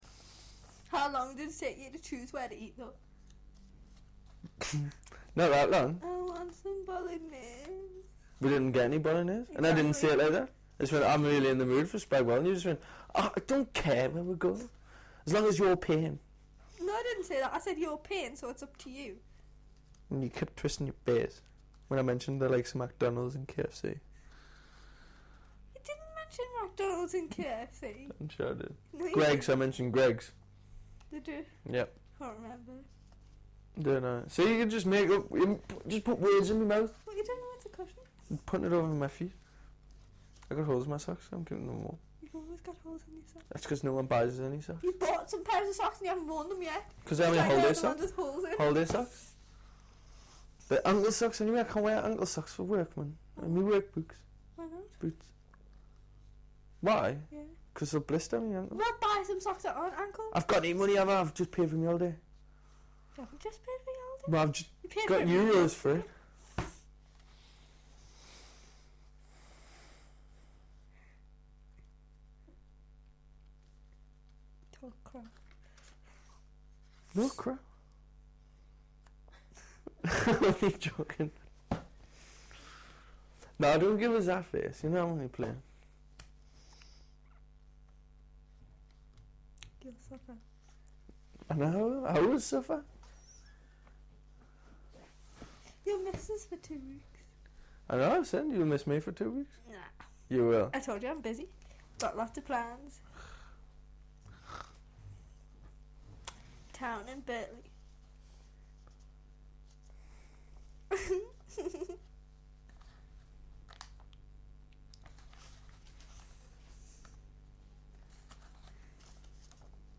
They are taken from the free conversation which was recorded as part of the fieldwork session. Informants were recorded in aged-matched pairs, as described above.
bullet Newcastle younger speakers 1 [
ncl-younger-1.mp3